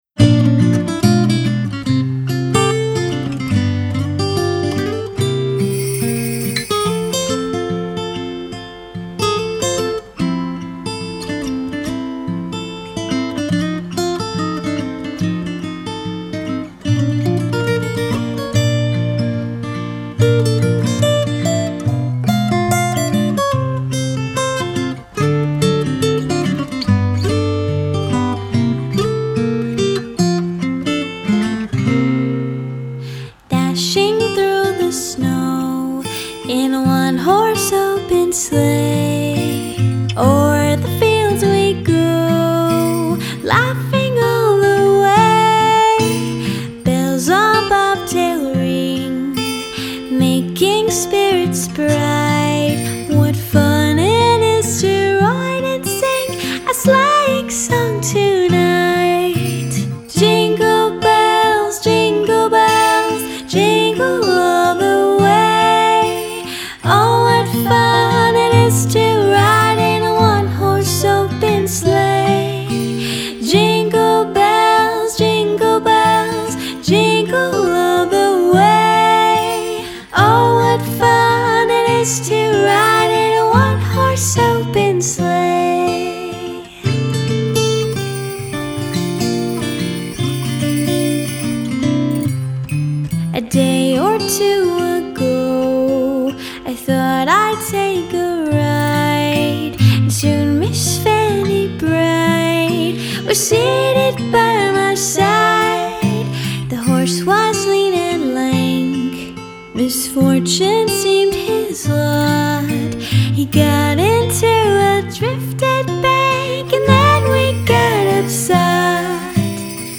vocals
Mixing-preview-for-Jingle-Bells.mp3